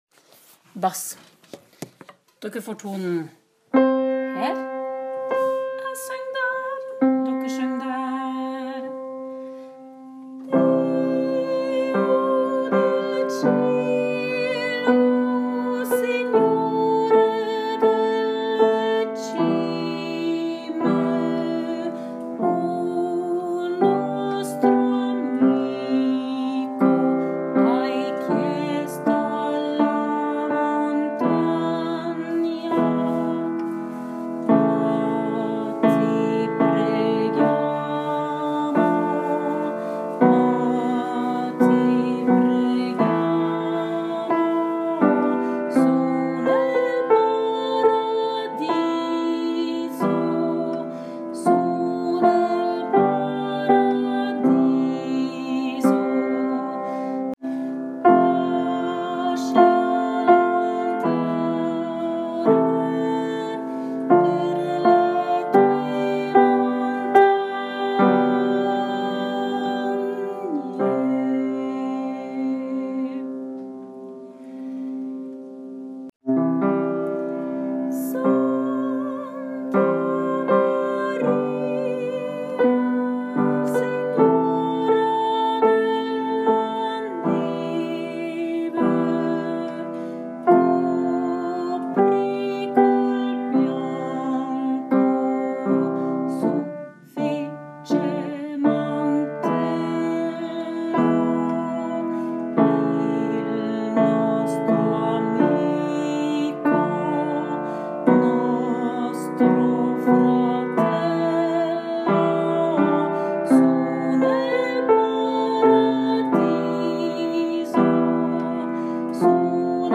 Bass
SignoreDelleCimeBass.m4a